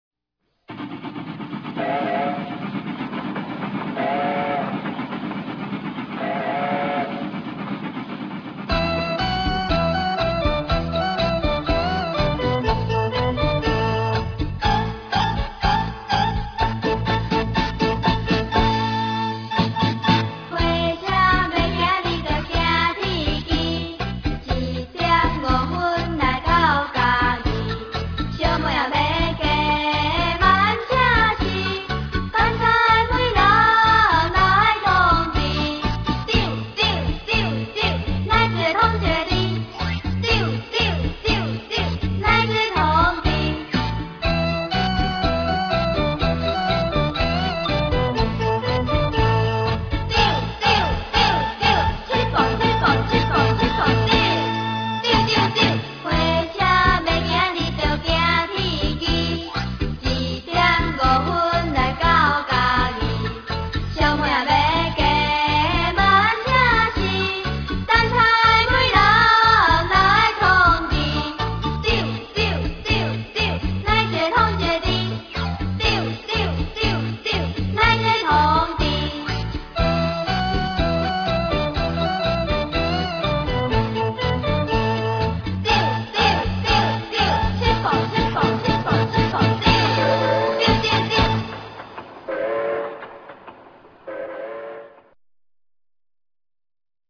傳統念謠